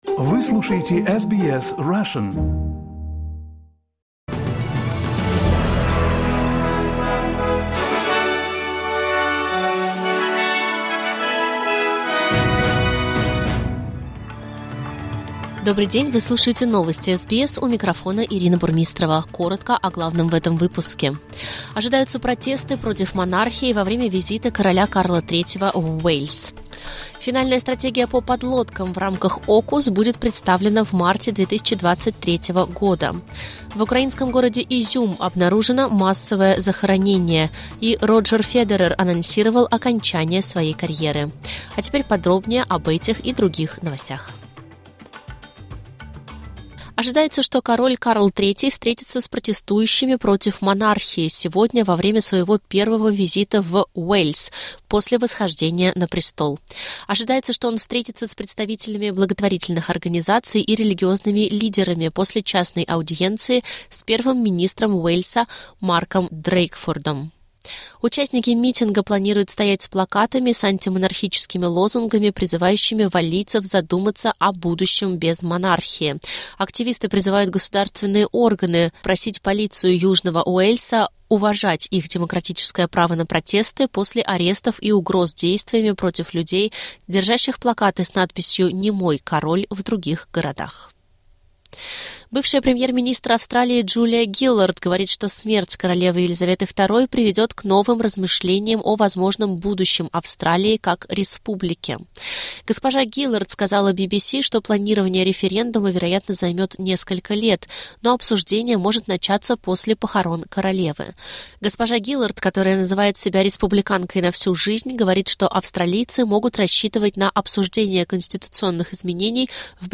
Listen to the top Australian and world news by SBS Russian.